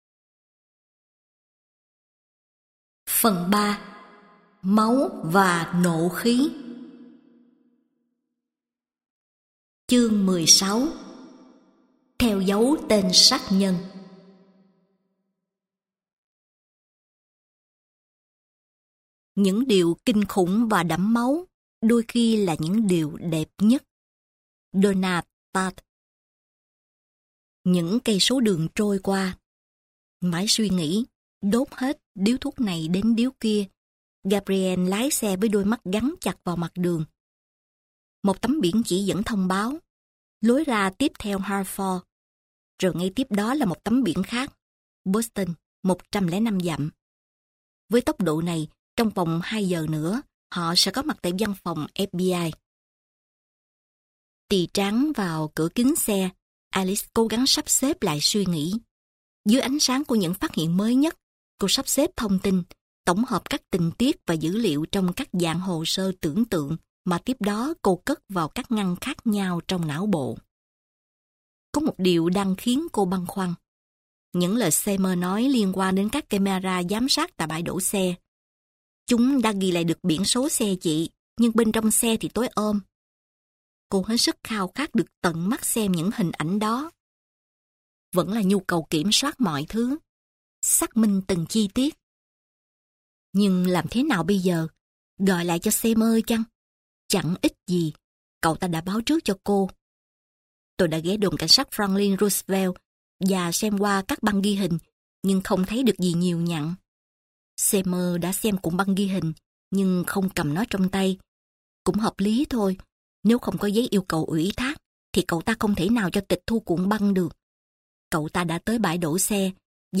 Sách nói Central Park - Tiểu Thuyết - Sách Nói Online Hay